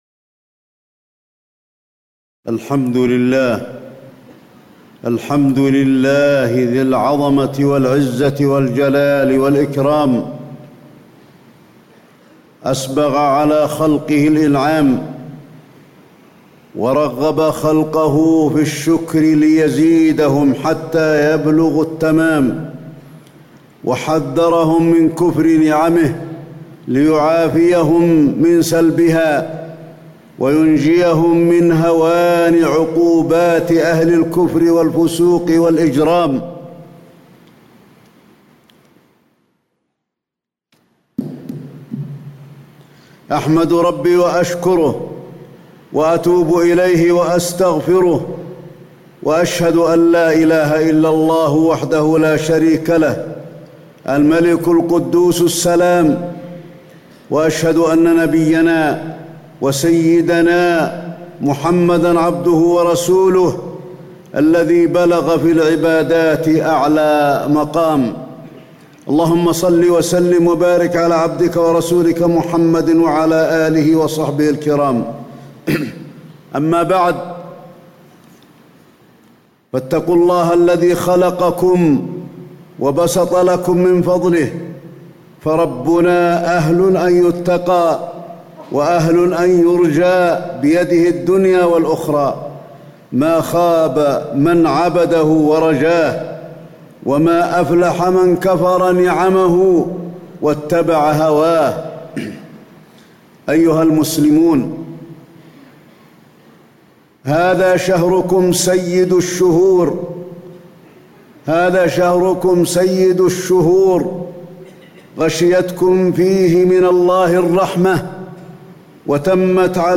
تاريخ النشر ٢٦ رمضان ١٤٣٧ هـ المكان: المسجد النبوي الشيخ: فضيلة الشيخ د. علي بن عبدالرحمن الحذيفي فضيلة الشيخ د. علي بن عبدالرحمن الحذيفي وداع شهر رمضان